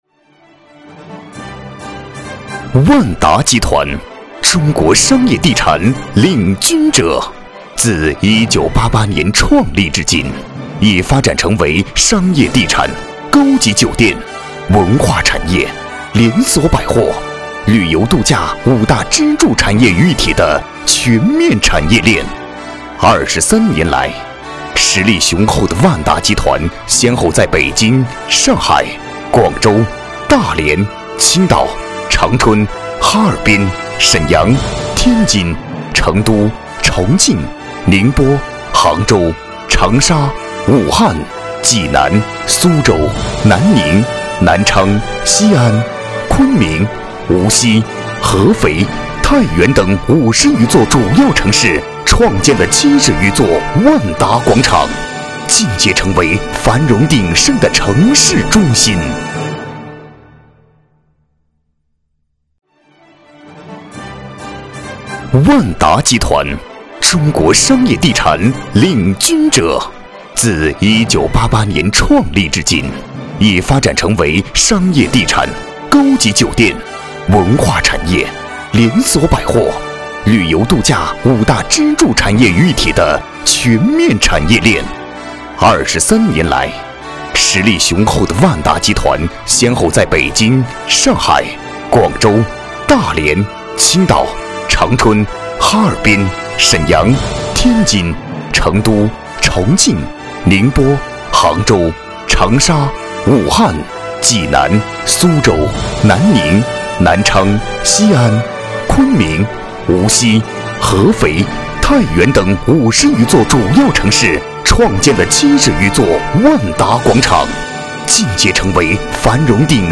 国语中年大气浑厚磁性 、沉稳 、男专题片 、宣传片 、80元/分钟男S337 国语 男声 专题片-大河宠物文化公园-大气、自然 大气浑厚磁性|沉稳